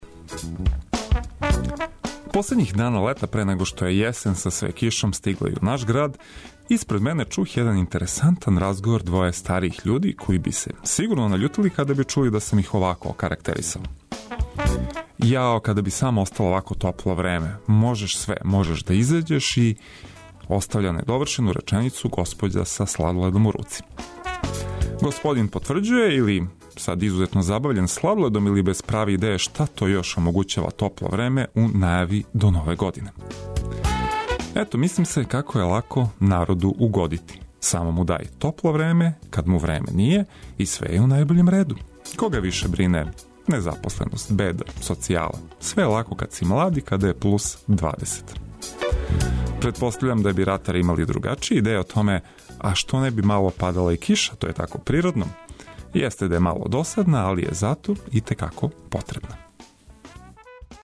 Одлична музика је неопходни бонус!